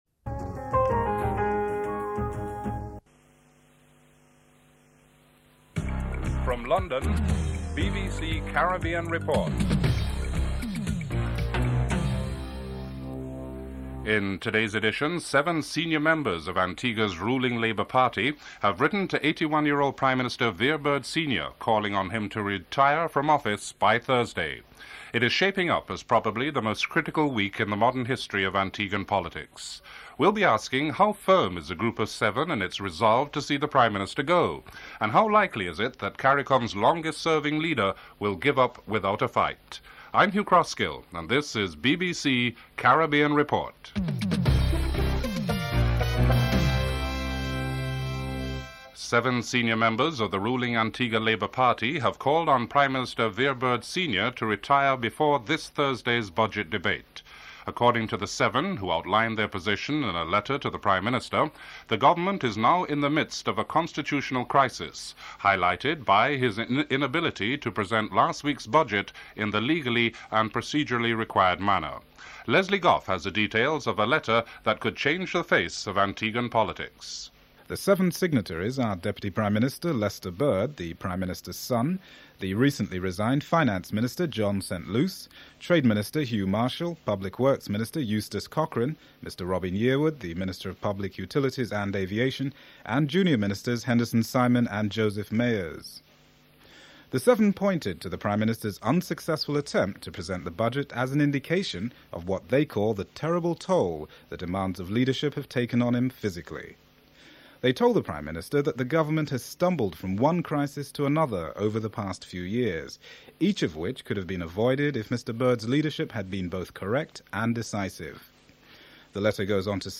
dc.description.tableofcontents1. Headlines (00:00 – 00:48)en_US